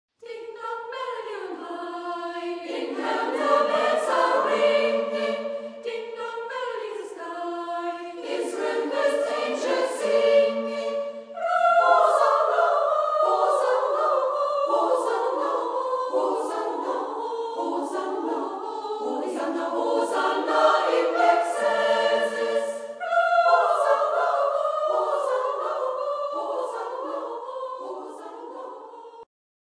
Wir haben die Titel beim Weihnachtskonzert 2008 in unserer Kirche in Veckenstedt eingesungen.